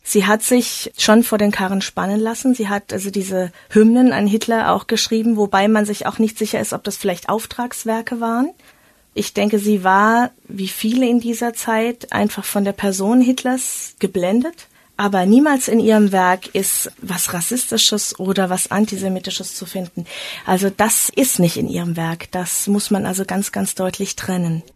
Die Sendung heißt „Zeitzeichen“ und beginnt mit seichter Klaviermusik und Gedichtzitaten von Agnes Miegel.